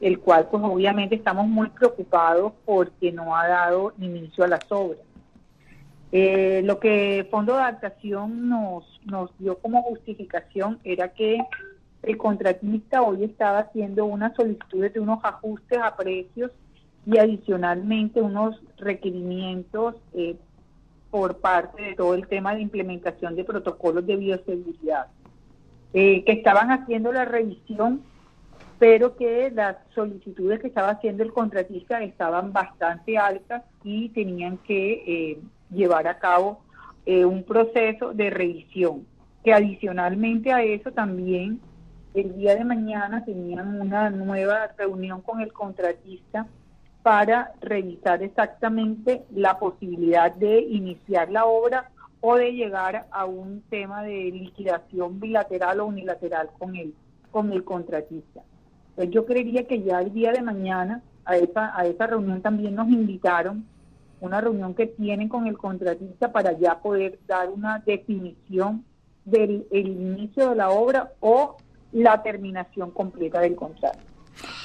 La secretaria de Infraestructura del departamento, Nury Logreira, expresó preocupación por esta situación, pero al mismo tiempo se mostró esperanzada en que se pueda llegar a una solución en esta nueva reunión prevista para este jueves.
VOZ-NURY-REUNION-FONDO.mp3